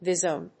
ヴィゾーネ